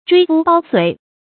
椎膚剝髓 注音： ㄓㄨㄟ ㄈㄨ ㄅㄠ ㄙㄨㄟˇ 讀音讀法： 意思解釋： 形容殘酷搜刮。